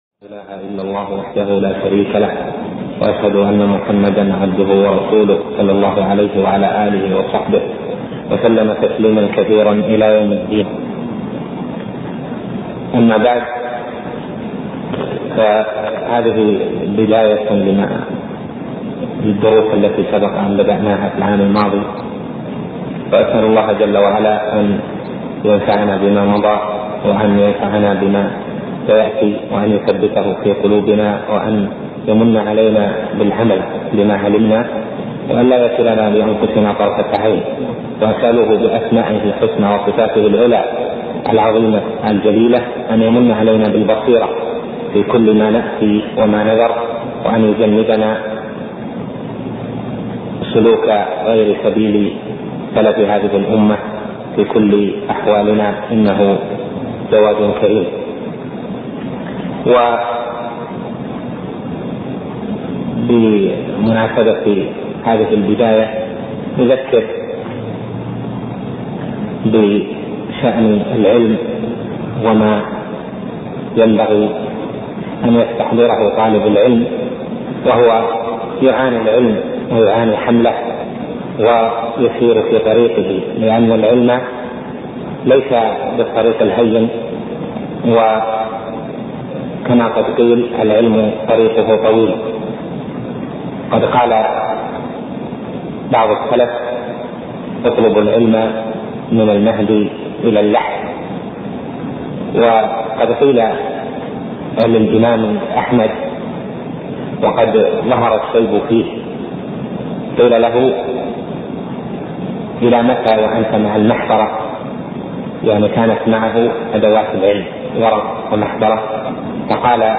محاضرة - أسباب الثبات على طلب العلم